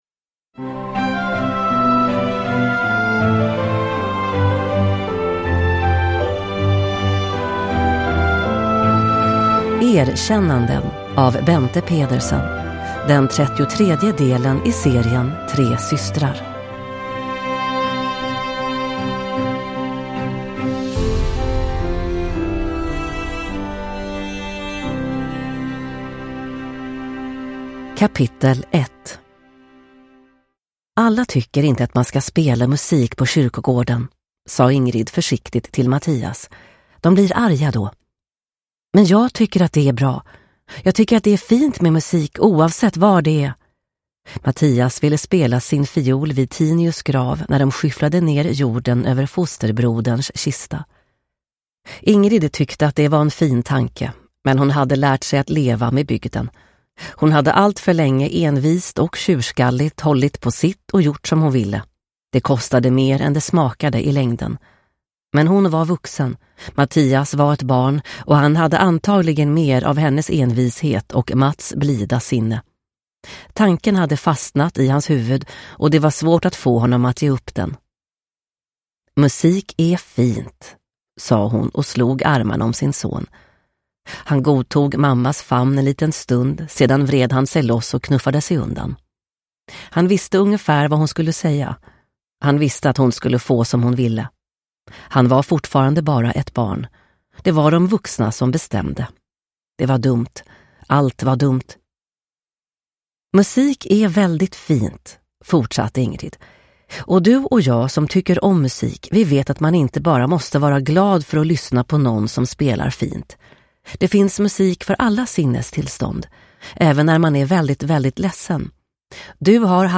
Erkännanden – Ljudbok – Laddas ner